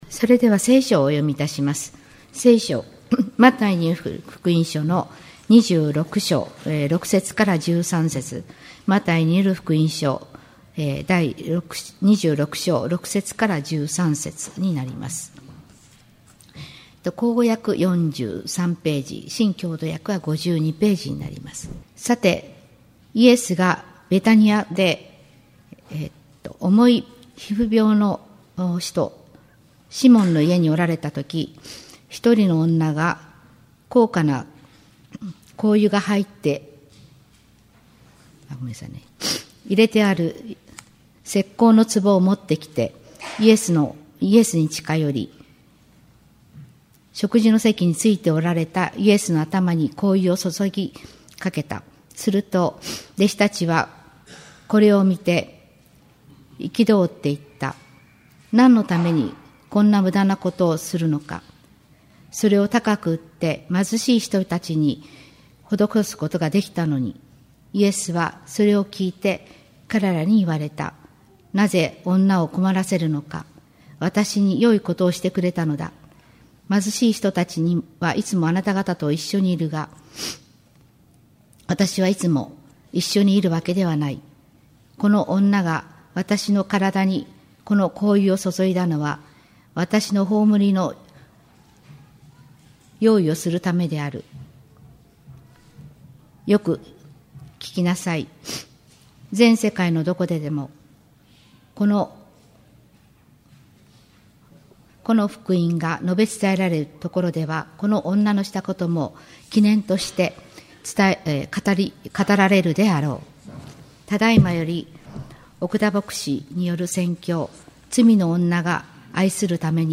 2018年1月21日（日）主日礼拝 | 東八幡キリスト教会